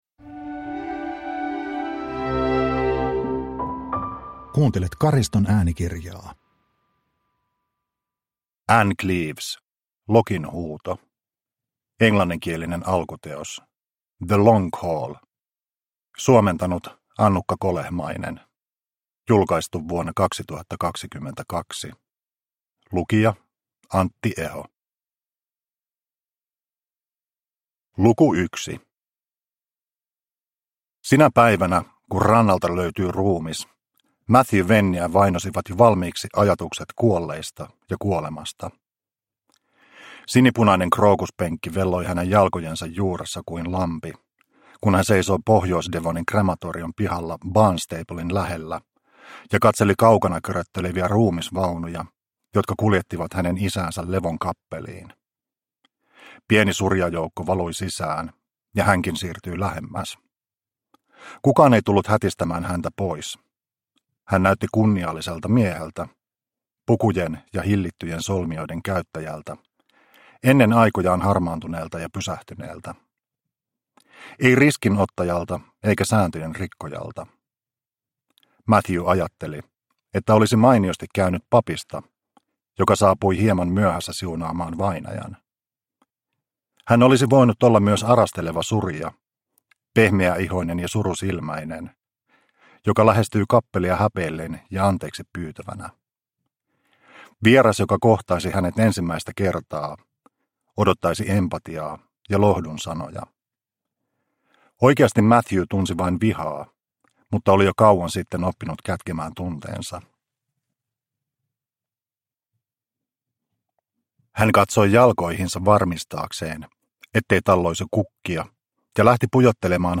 Lokin huuto – Ljudbok – Laddas ner